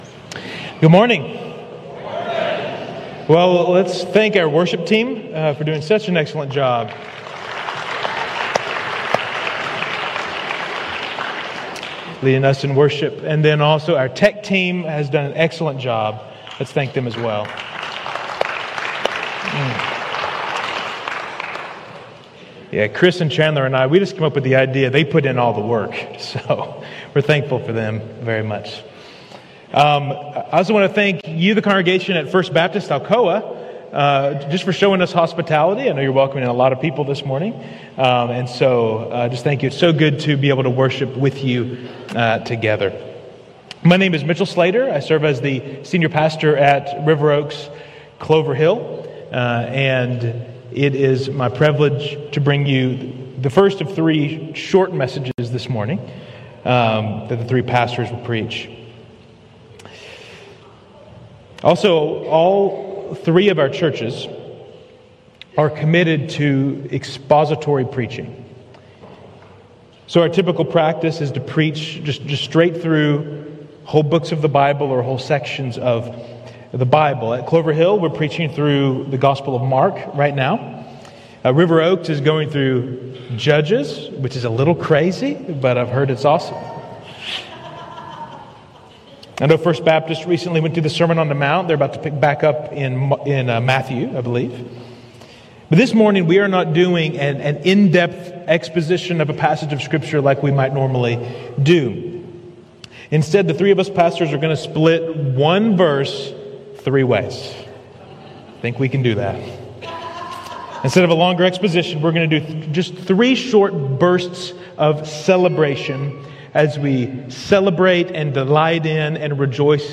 Joint Service: First Baptist Alcoa, River Oaks Community Church, River Oaks and Clover Hill